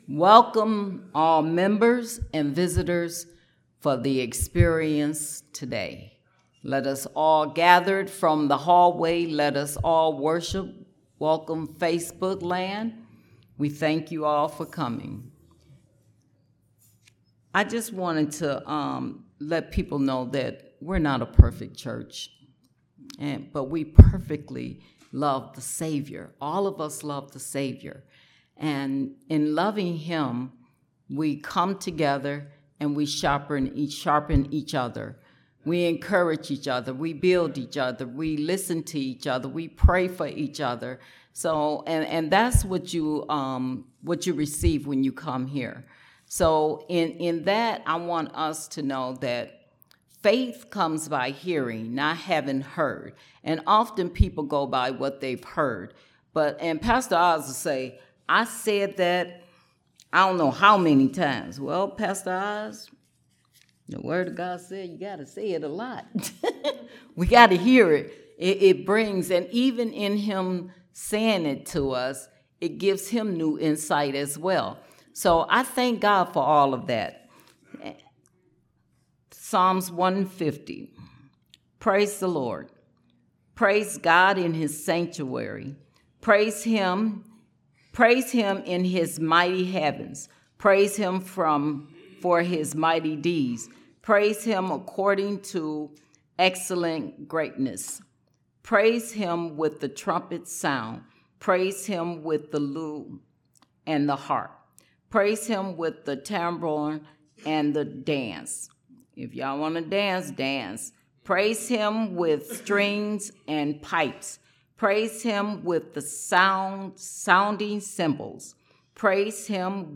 Series: Prophetic Nature of the Church Service Type: Sunday Service